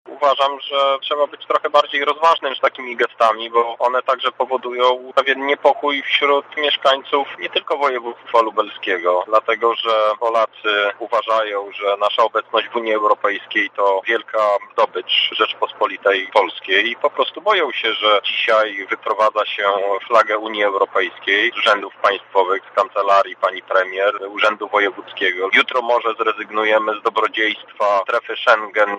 – mówi europoseł Krzysztof Hetman